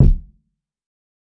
TC Kick 20.wav